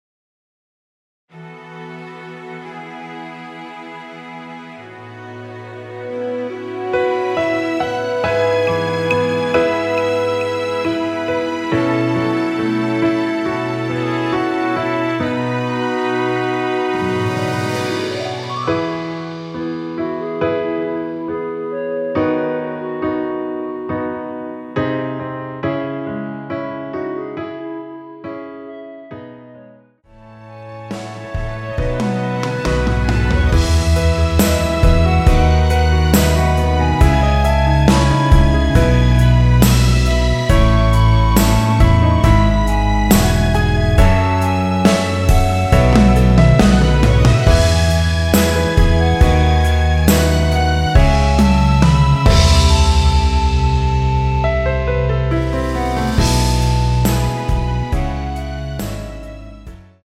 여성분이 부르실수 있는키의 MR입니다.
원키에서(+3)올린 멜로디 포함된 MR입니다.
앞부분30초, 뒷부분30초씩 편집해서 올려 드리고 있습니다.